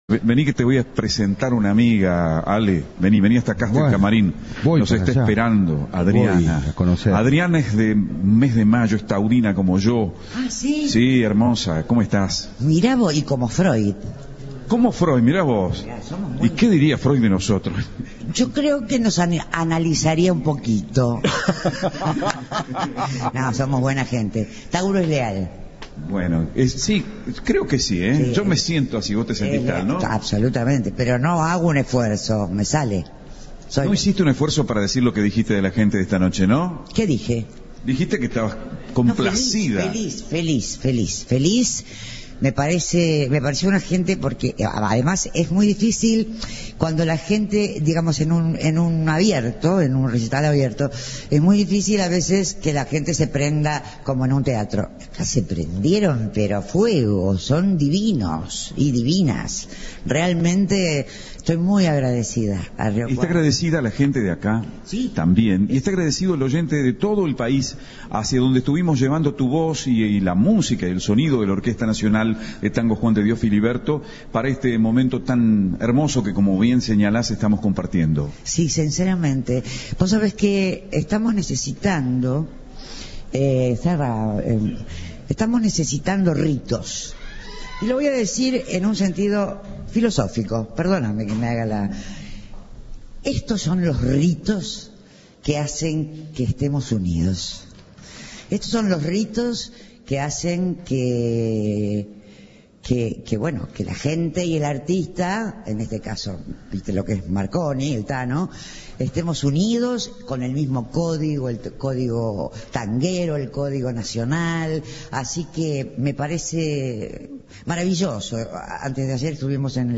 La cantante cautivó a todo Río Cuarto con su potente voz y habló en exclusiva con Cadena 3 tras un recital que se llevó los elogios del público.
Otra velada encantadora se vivió este sábado en el anfiteatro de Río Cuarto con la actuación de Adriana "La Gata" Varela en la sexta noche del Festival Otoño Polifónico.